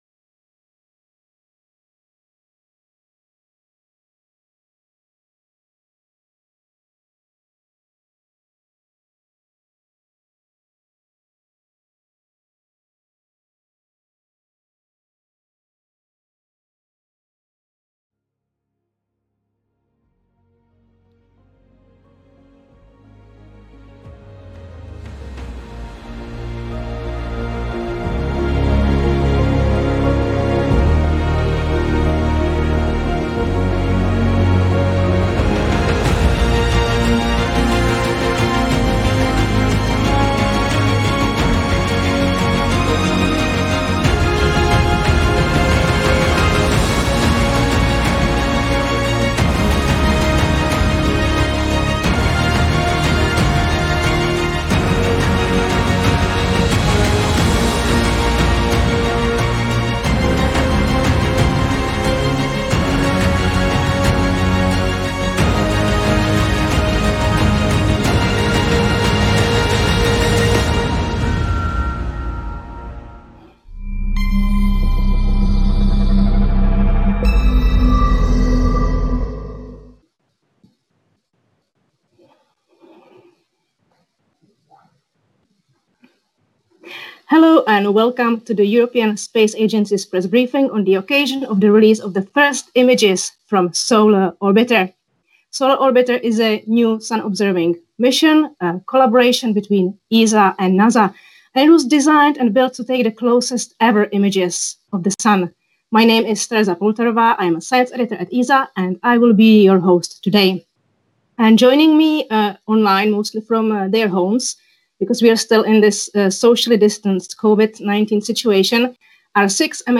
Scientists from ESA (European Space Agency) and NASA will present the first images captured by Solar Orbiter, the joint ESA/NASA mission to study the Sun, during an online news briefing at 8 a.m. EDT Thursday, July 16.